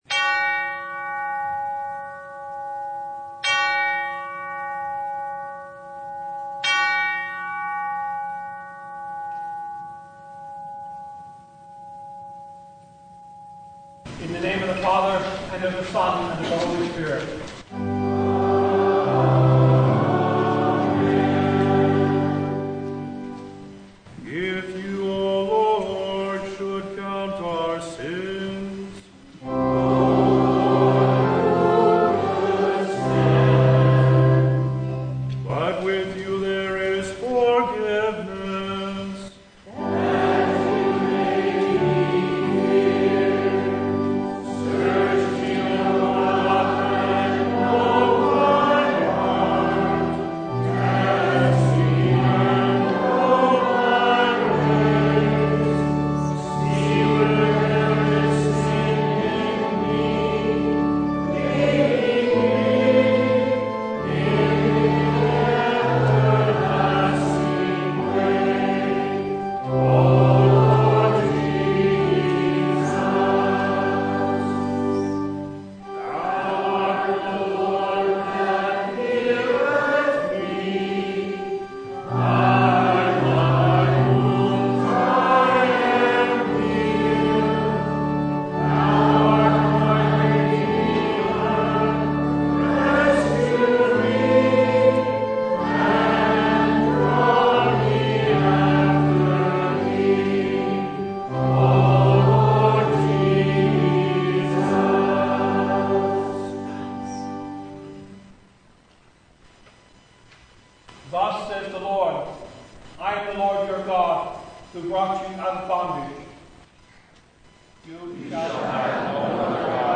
Service Type: Service of the Word
Topics: Full Service